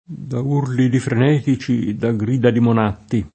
DOP: Dizionario di Ortografia e Pronunzia della lingua italiana
frenetico [fren$tiko] agg. («vaneggiante; convulso; concitato»); pl. m. -ci — pl. -ci anche se sostantivato: da urli di frenetici, da grida di monatti [